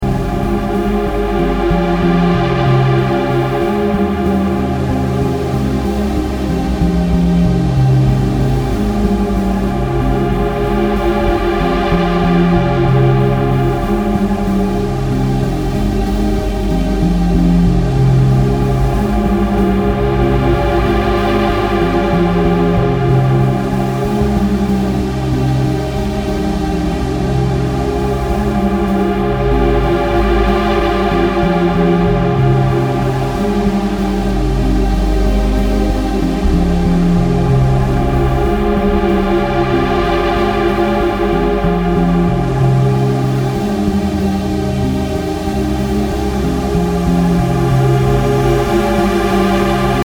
Ambient, Drone >